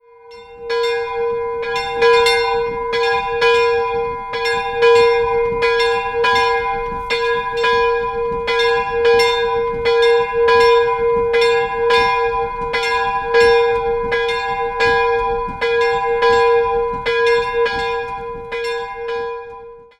2-stimmiges Geläute: as''-c''' Die größere Glocke wurde im Jahr 1651 von Georg Schelchshorn in Regensburg gegossen, die kleinere 1742 von J. G. Neuber in Ingolstadt. Beide Glocken werden per Hand geläutet.